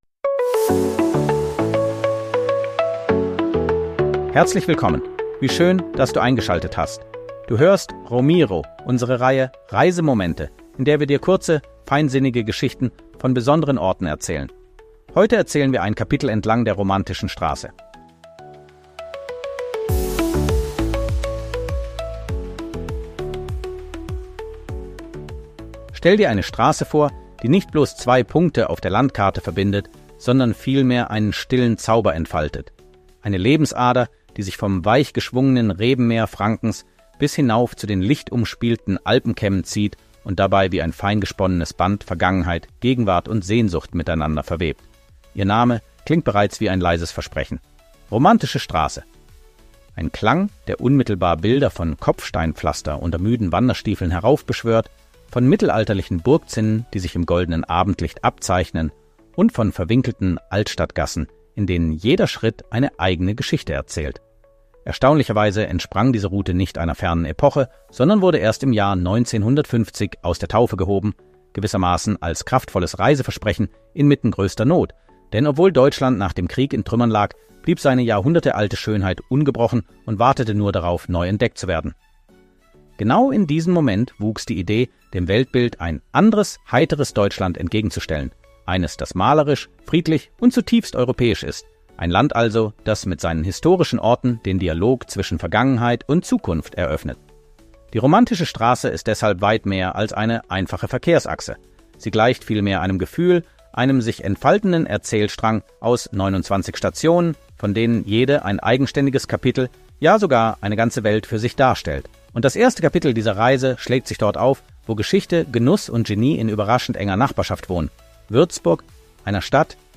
Diese Folge ist kurz, atmosphärisch